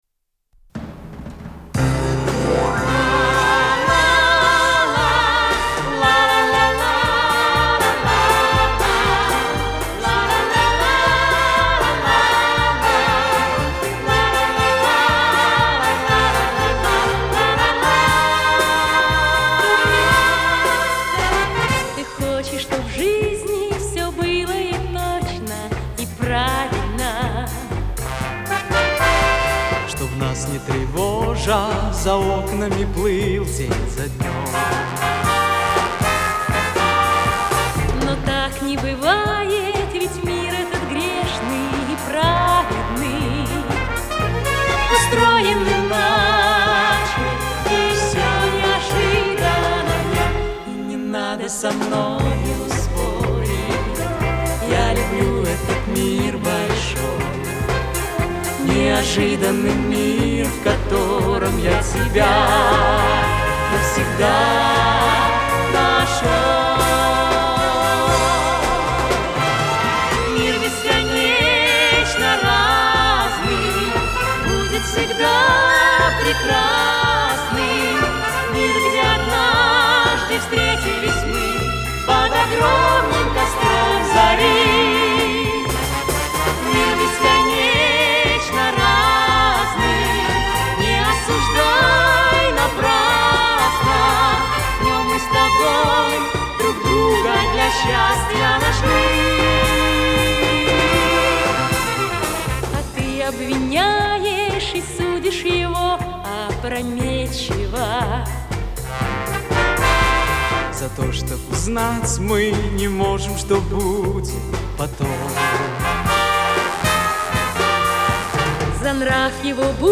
Припев своеобразный, ни как у Самоцветов...